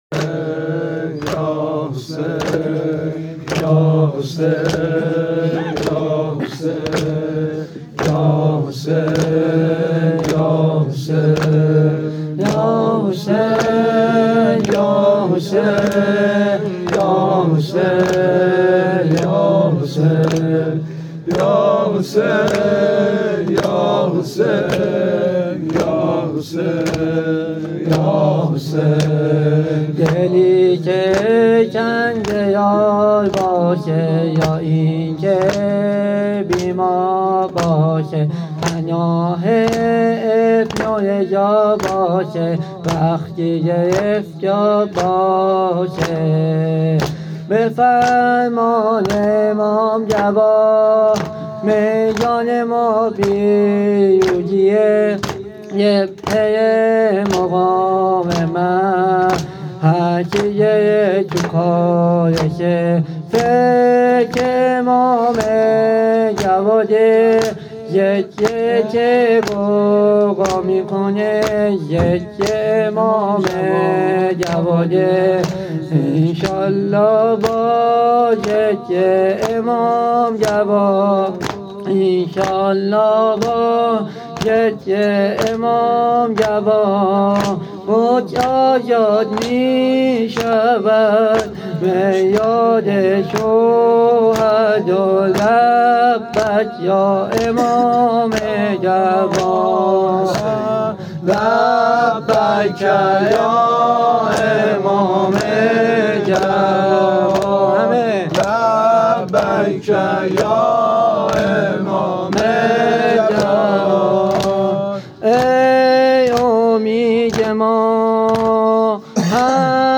هیئت محبین الزهرا تهران